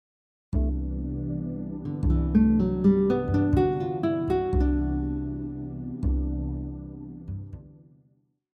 For this example, we are imposing a G Major 7 arpeggio over the C Major 7 chord. This creates a lydian sound which highlights the raised 4th degree.
Major-7-arpeggio-example-4.mp3